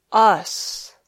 Click on each word to hear it and practice its pronunciation.